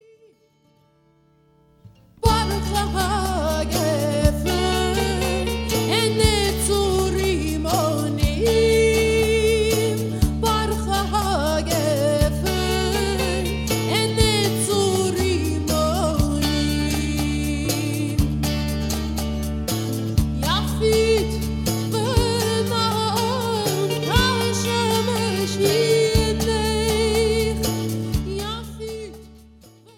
Yemenite Folk